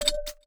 Universal UI SFX / Clicks
UIClick_Soft Tonal 02.wav